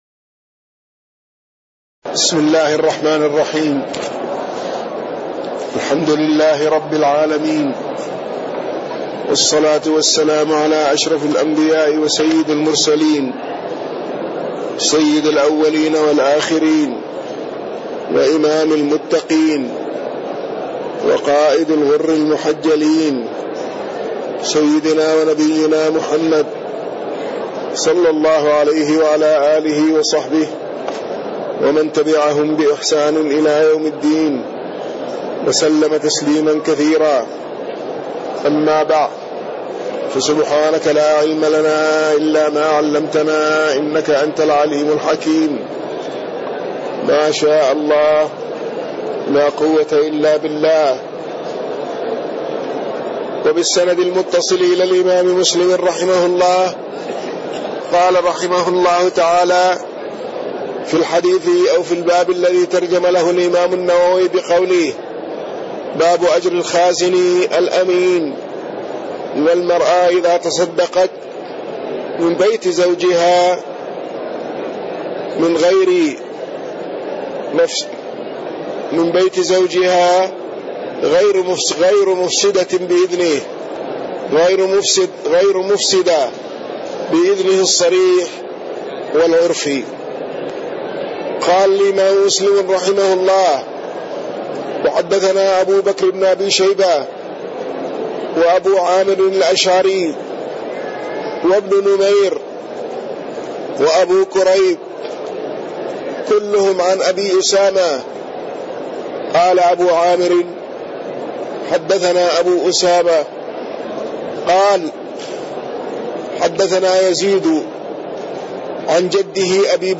تاريخ النشر ١٧ شعبان ١٤٣٢ هـ المكان: المسجد النبوي الشيخ